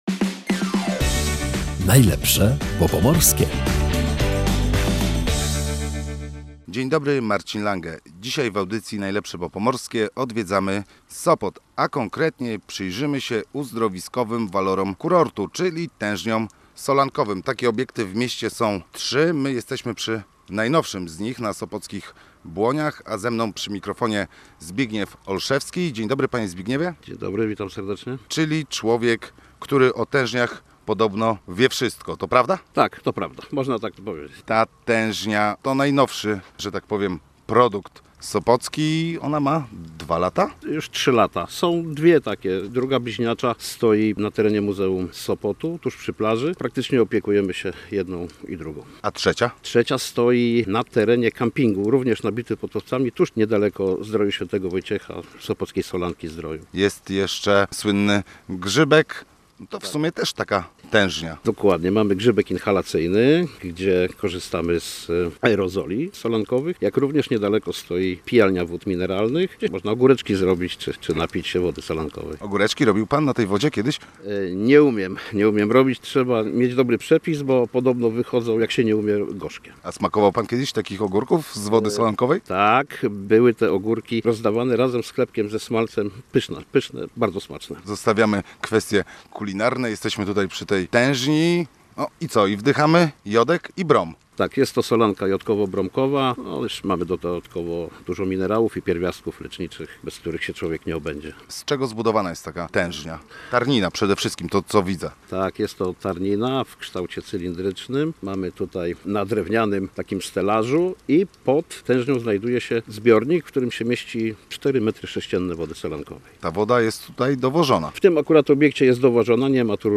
W tej audycji z cyklu "Najlepsze, bo pomorskie" odwiedzamy Sopot. A konkretnie przyglądamy się uzdrowiskowym walorom kurortu, czyli tężniom solankowym.